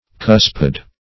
Cuspid \Cus"pid\ (k[u^]s"p[i^]d), n. [See Cusp.]